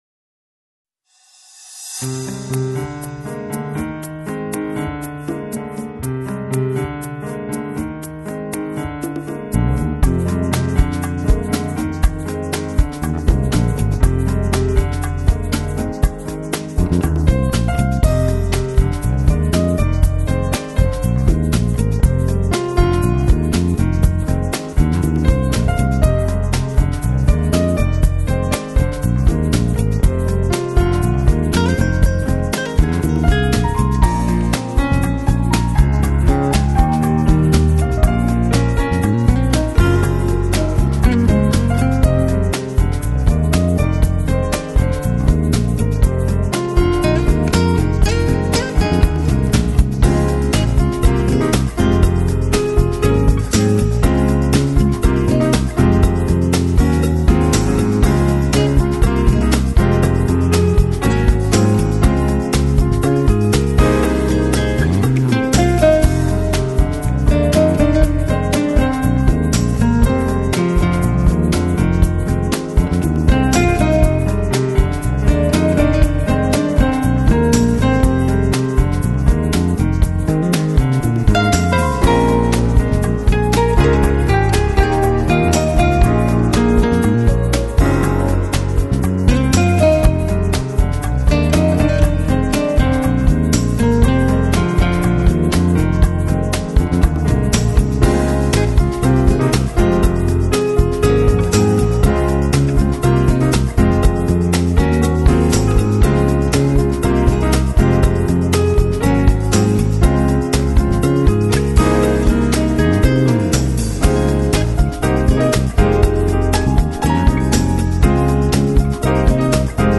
Жанр: Smooth Jazz